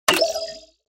دانلود آهنگ کلیک 10 از افکت صوتی اشیاء
دانلود صدای کلیک 10 از ساعد نیوز با لینک مستقیم و کیفیت بالا
جلوه های صوتی